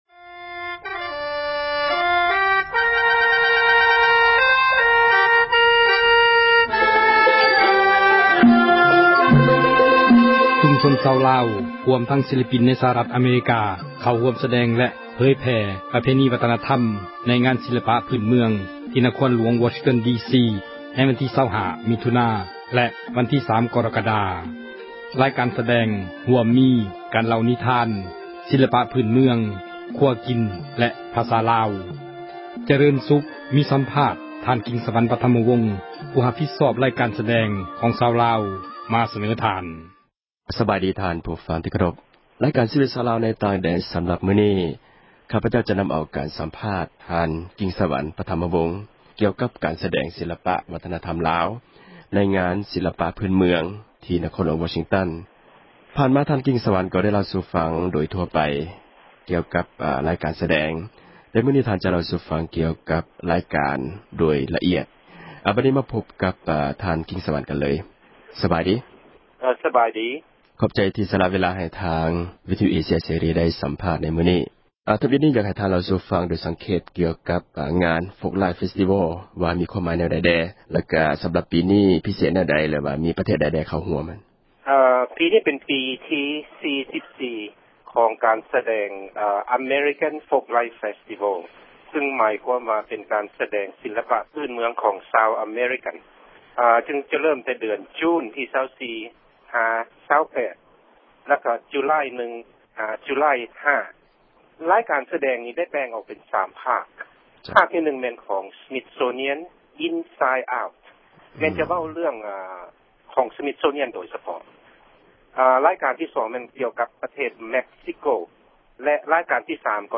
ມີສັມພາດ